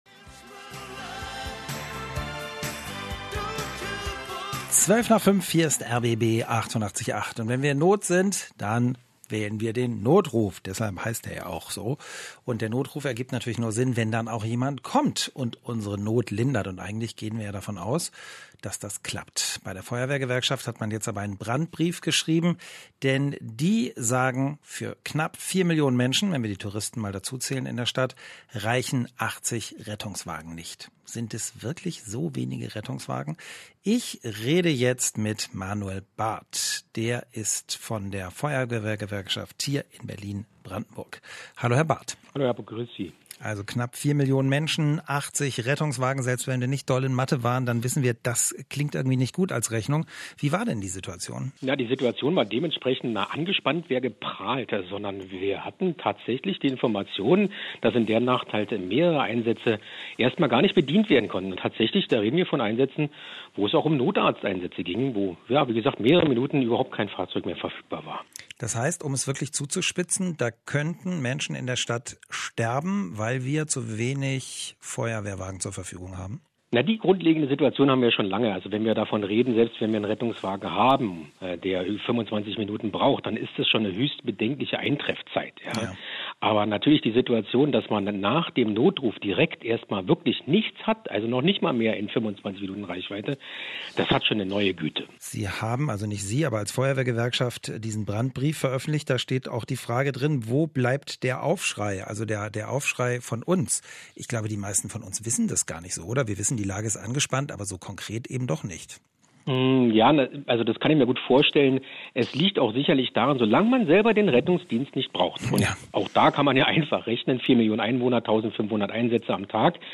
Radio Mitschnitt vom RBB >> 88.8 vom 24.08.22